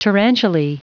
Prononciation du mot tarantulae en anglais (fichier audio)
Prononciation du mot : tarantulae